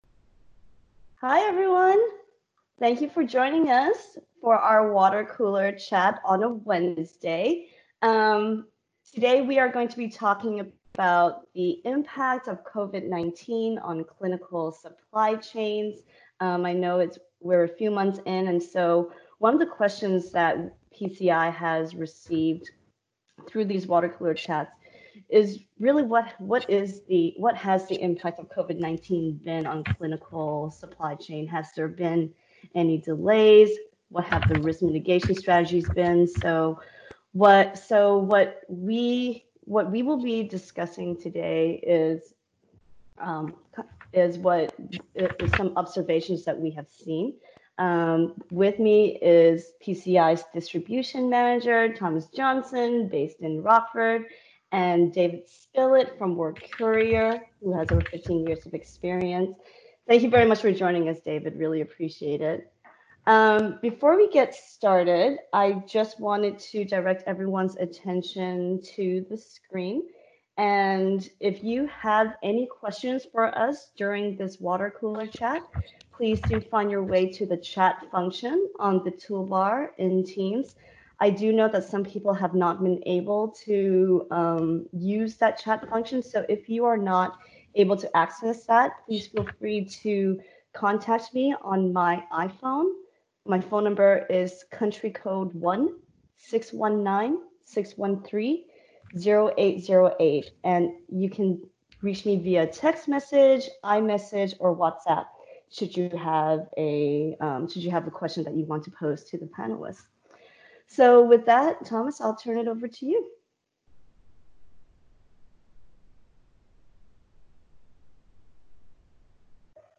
Water Cooler Chat Session: COVID-19 Impact on Clinical Supply Chains | Recorded May 27, 2020 - PCI Services